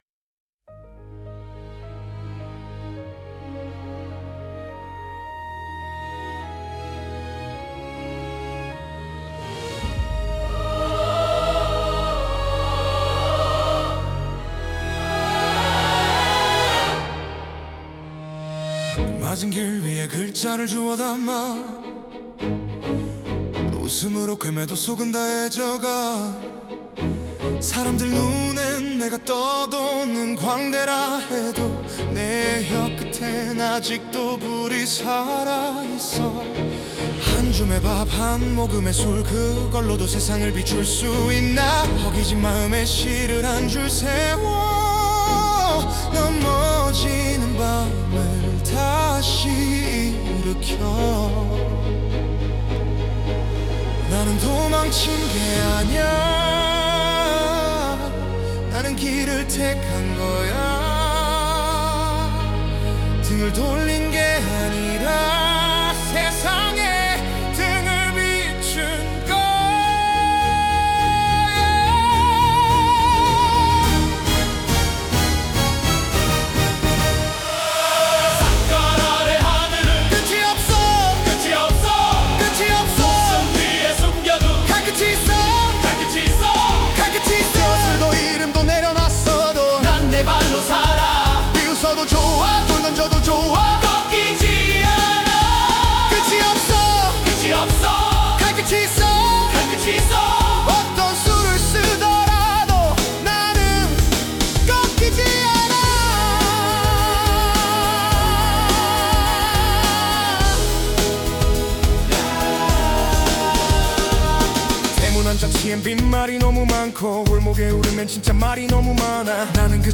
다운로드 설정 정보 Scene (장면) Topic (주제) Suno 생성 가이드 (참고) Style of Music Female Vocals, Soft Voice Lyrics Structure [Meta] Language: Korean Topic: [Verse 1] (조용한 시작, 의 분위기를 묘사함) ... [Pre-Chorus] (감정이 고조됨) ...